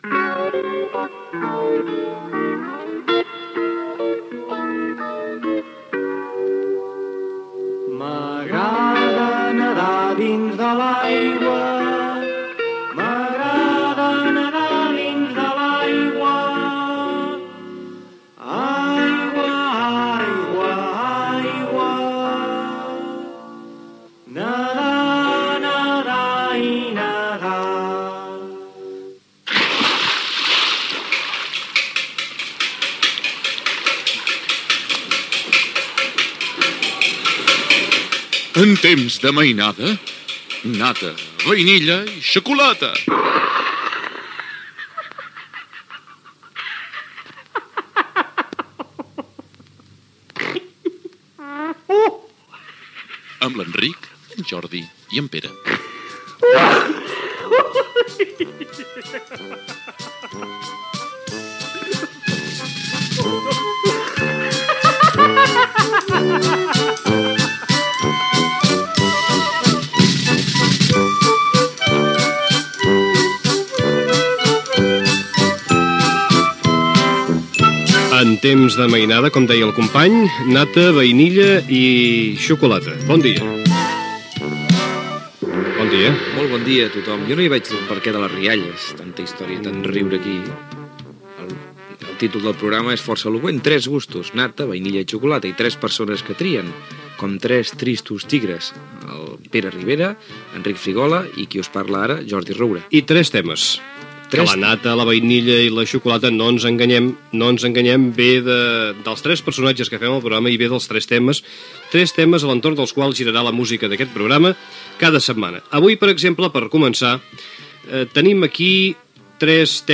Careta del programa, equip, presentació i tema musical
Musical